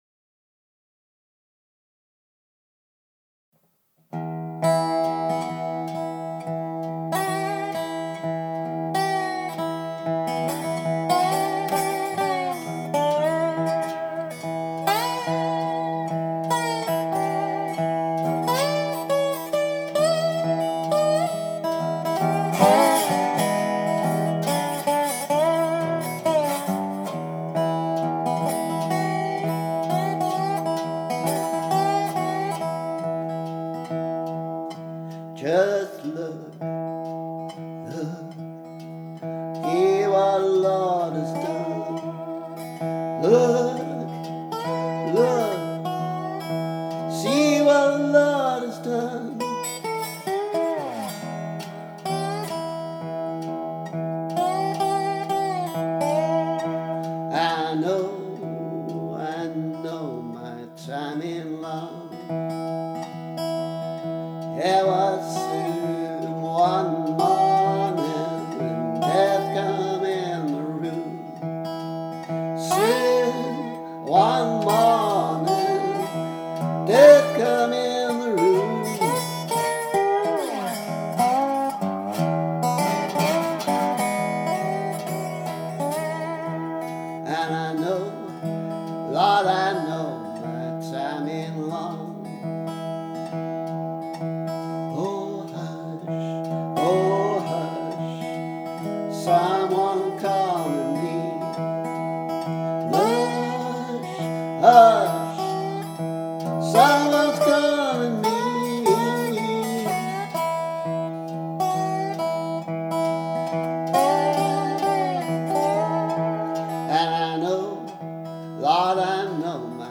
Not sure the world needs my version of this, but playing with an arrangement anyway, in case I ever take the resonator out in public again…